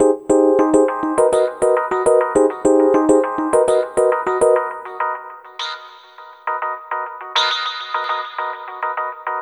Ala Brzl 1 Piano-F.wav